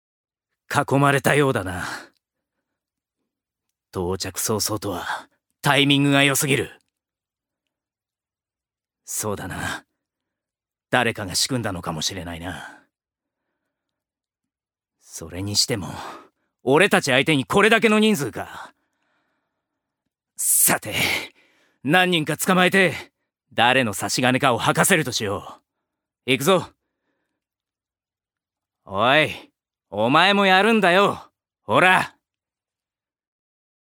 ジュニア：男性
音声サンプル
セリフ１